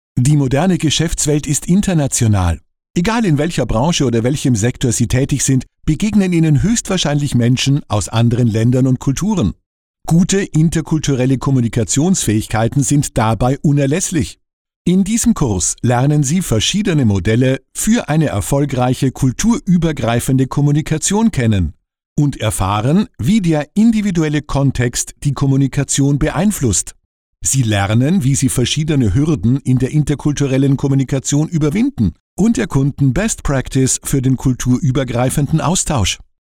Sprechprobe: eLearning (Muttersprache):
German voice artist for Radio, TV, Audio-Book, Commercials, E-Learning